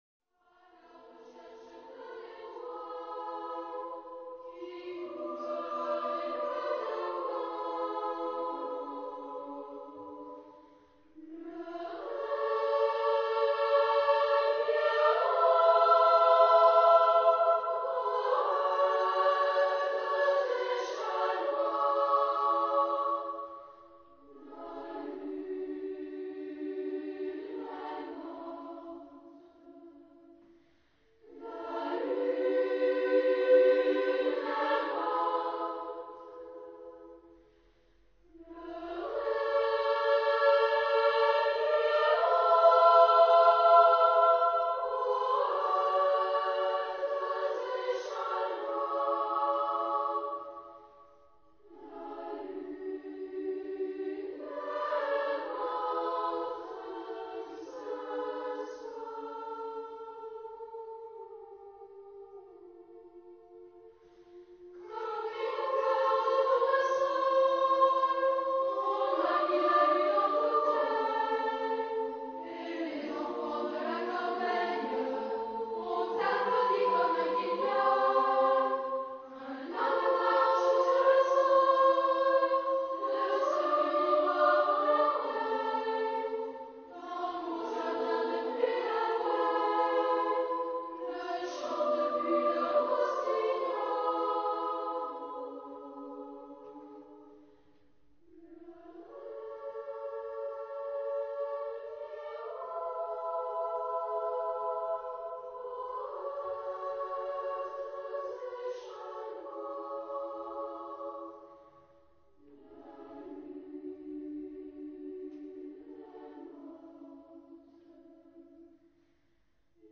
... Harmonisations à 4 voix mixtes et à 3 voix égales ...
Genre-Style-Forme : Moderne ; Chanson ; Profane ; Variété
Caractère de la pièce : mélancolique ; triste
Tonalité : la majeur